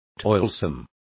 Complete with pronunciation of the translation of toilsome.